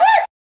Yes Indeed Vox.wav